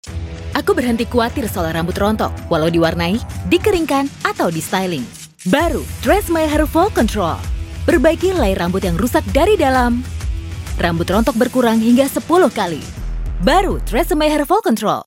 Voice Samples
female